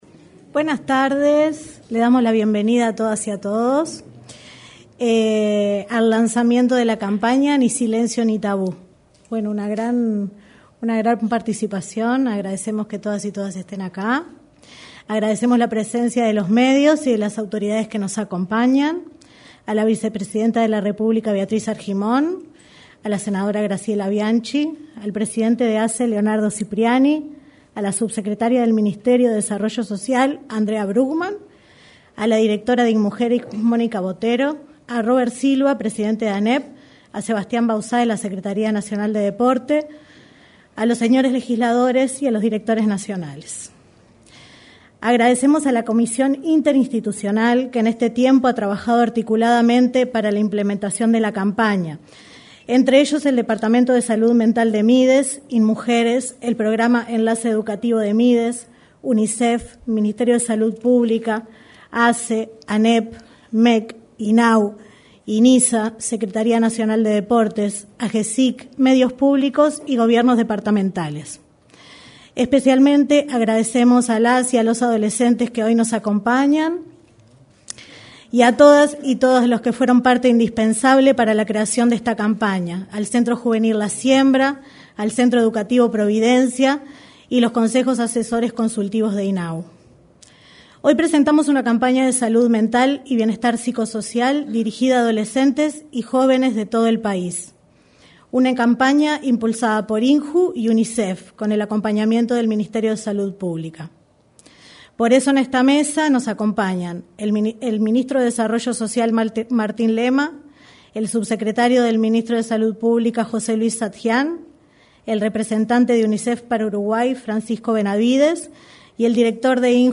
Lanzamiento de campaña de salud mental del Mides y Unicef 27/07/2022 Compartir Facebook X Copiar enlace WhatsApp LinkedIn Este miércoles 27, se realizó el lanzamiento de la campaña nacional para sensibilizar sobre salud mental y bienestar psicosocial dirigida a jóvenes y adolescentes. En el evento, participaron la vicepresidenta de la República, Beatriz Argimón; el secretario de la Presidencia, Álvaro Delgado, y otras autoridades nacionales.